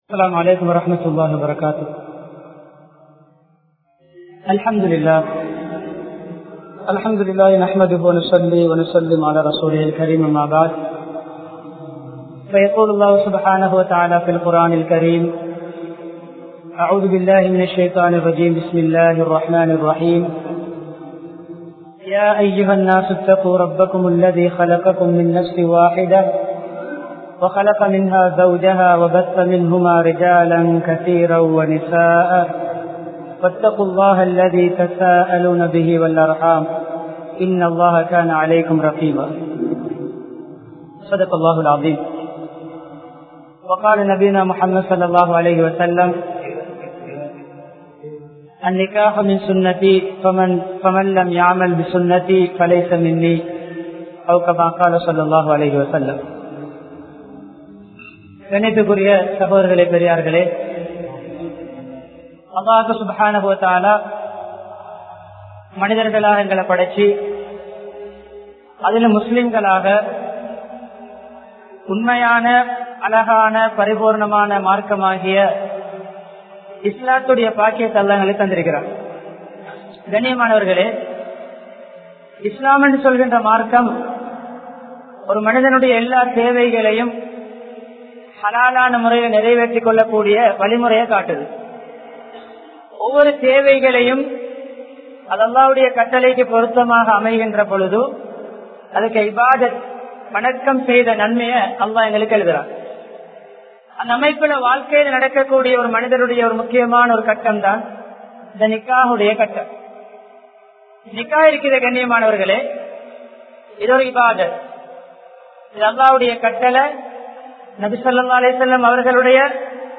Kudumba Vaalkaiyai Seerkedukkum Vidayankal(குடும்ப வாழ்க்கைய சீரழிக்கும் விடயங்கள்) | Audio Bayans | All Ceylon Muslim Youth Community | Addalaichenai
Colombo, Maradana Zahira Jumua Masjith